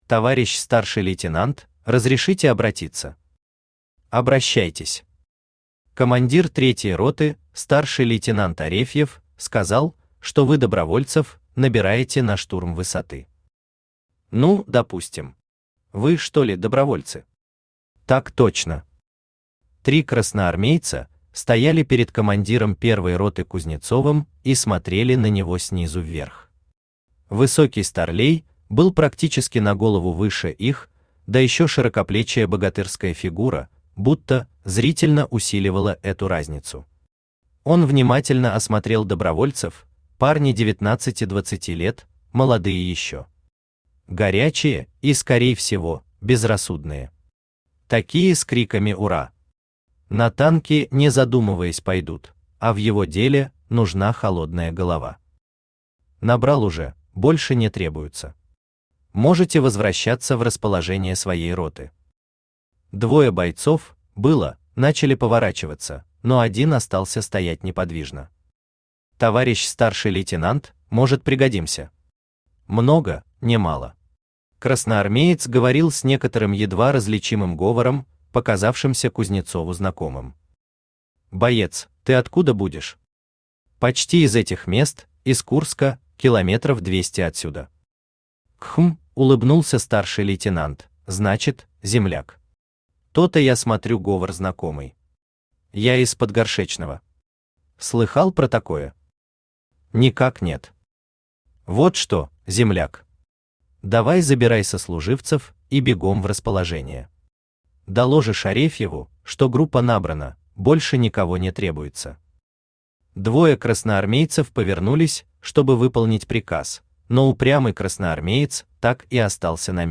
Аудиокнига Закалённый сталью | Библиотека аудиокниг
Aудиокнига Закалённый сталью Автор Евгений Витальевич Новиков Читает аудиокнигу Авточтец ЛитРес.